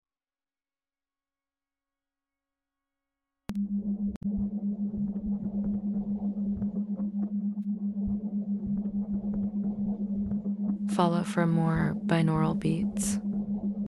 Binaural Beats part 2! sound effects free download
Mp3 Sound Effect Binaural Beats part 2! 6.3 Hz — said to activate the astral mind Some say it unlocks mental travel…